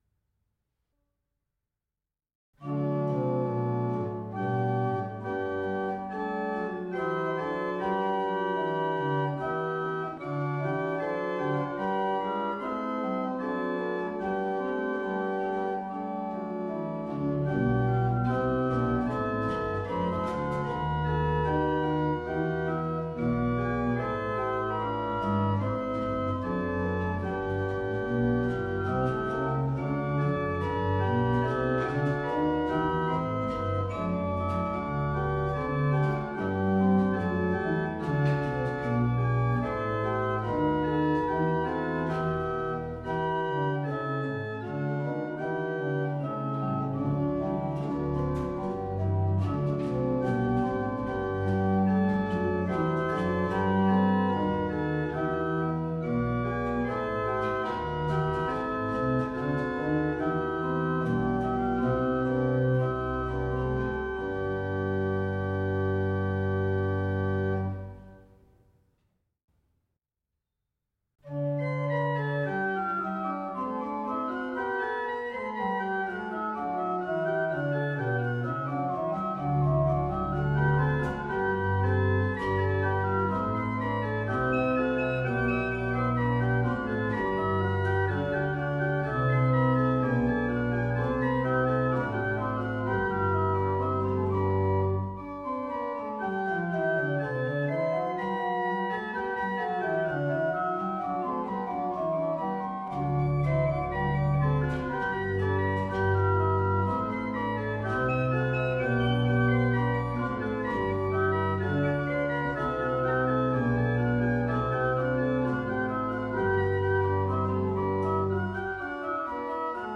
2025 Zuidlaren N.H.Kerk Meere 1787